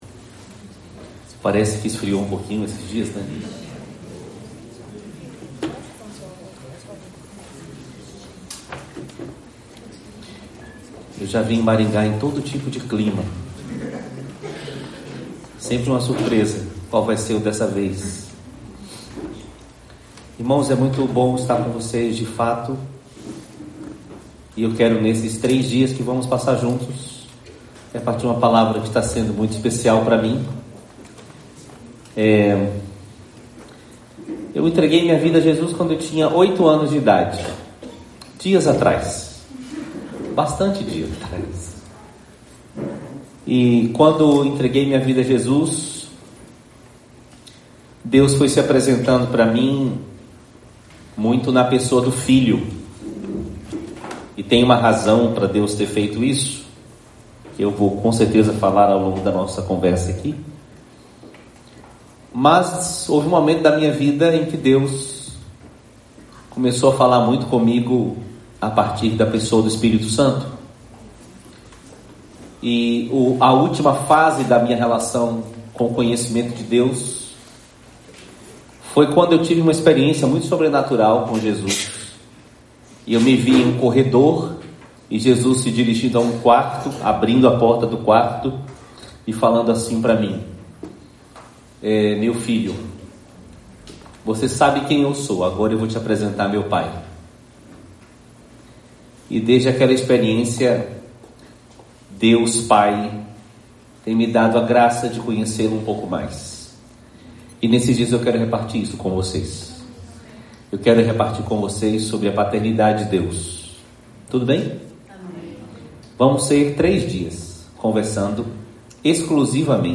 Palavra ministrada
no Encontro de Famílias 2025